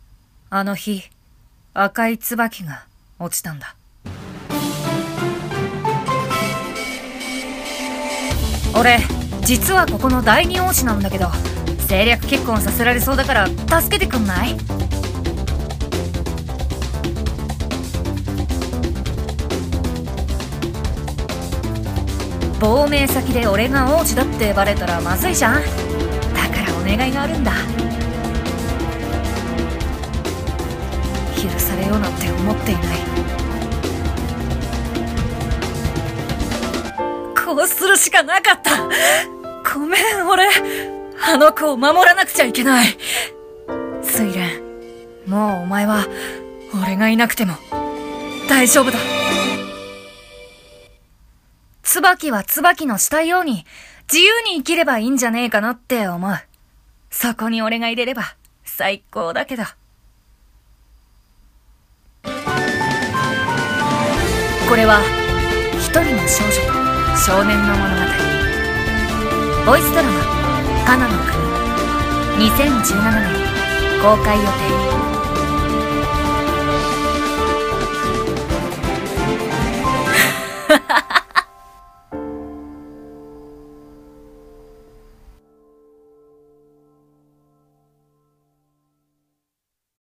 CM風声劇「華那ノ國」